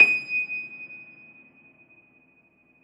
53g-pno23-D5.wav